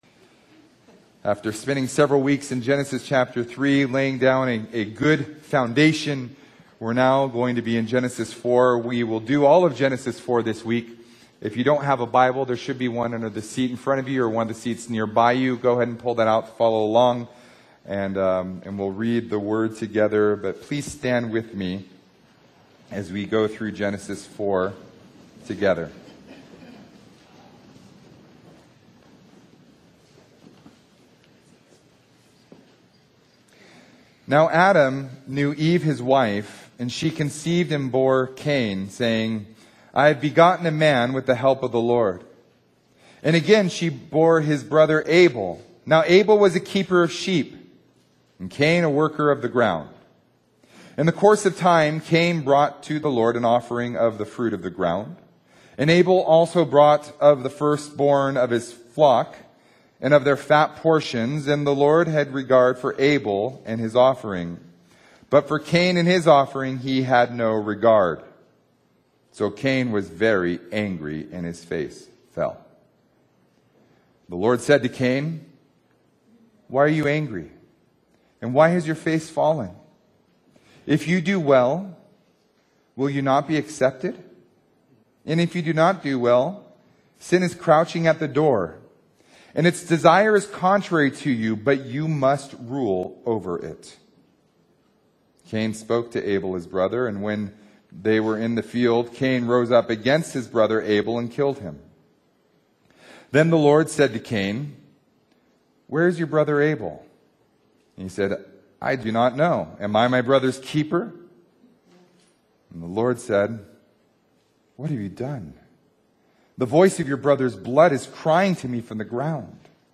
Download Audio Sermon Notes Facebook Tweet Link Share Link Send Email